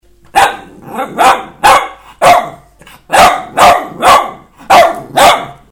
J'aboie
loulouaboie2.mp3